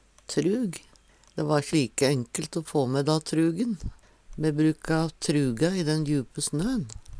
trug - Numedalsmål (en-US)